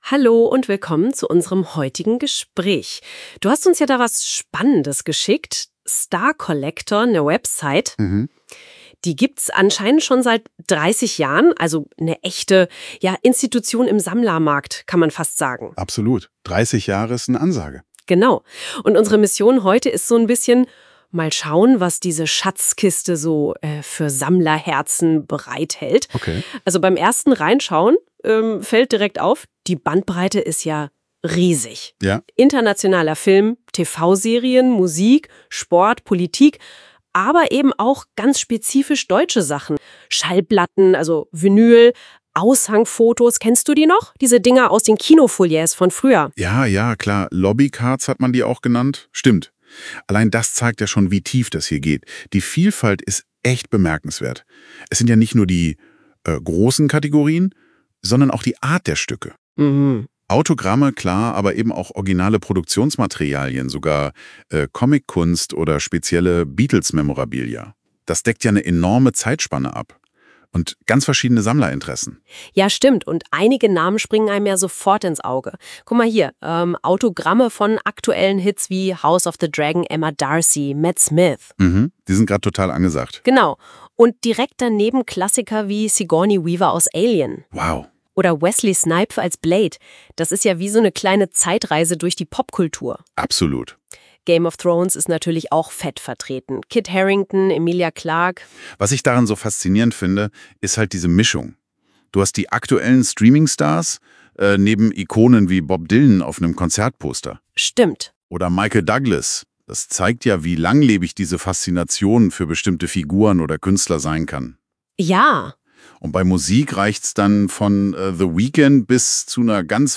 Hier ein interessantes Gespräch zu Starcollector:
(Bild + Audio: KLE-Blatt + KI)